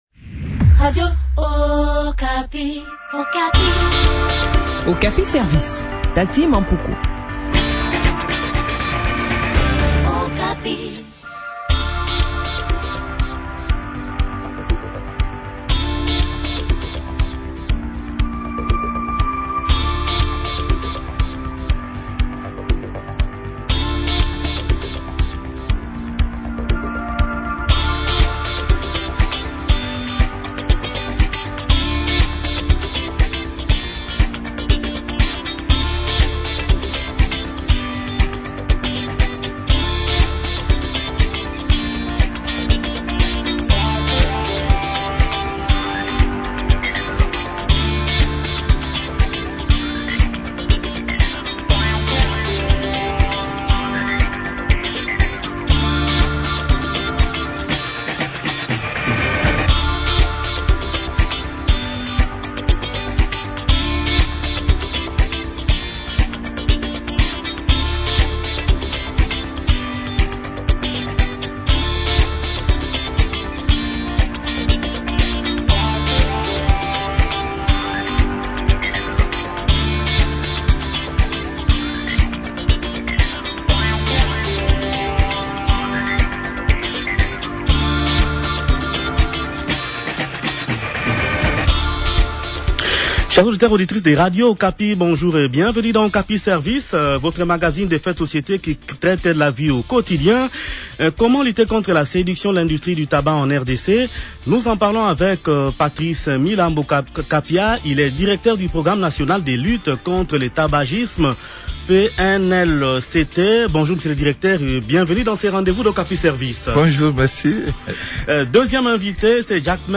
s’entretient sur ce sujet